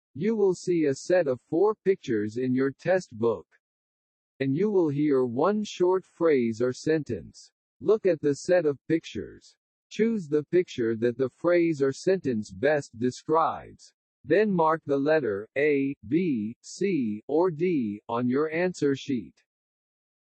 （音声は個人的にパソコンを使って作成したもので、本物の話者ではありません。